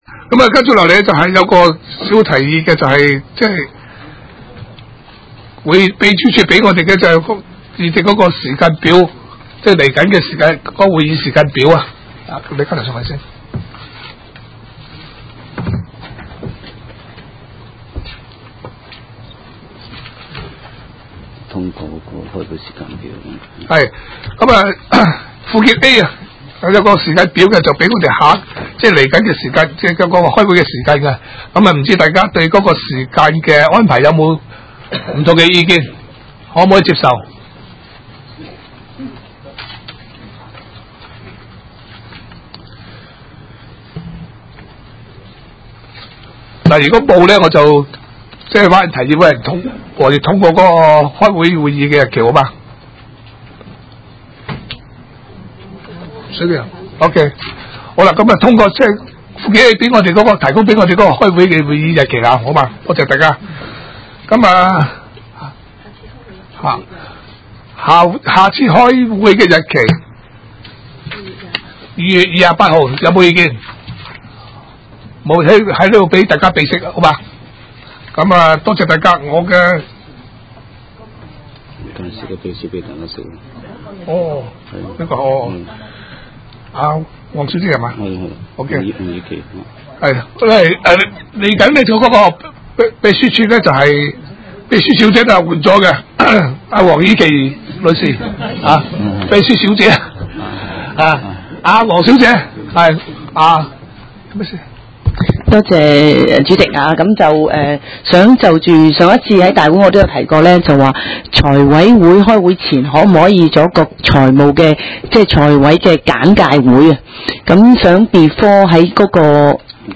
委员会会议的录音记录
地点: 将军澳坑口培成路38号 西贡将军澳政府综合大楼三楼 西贡区议会会议室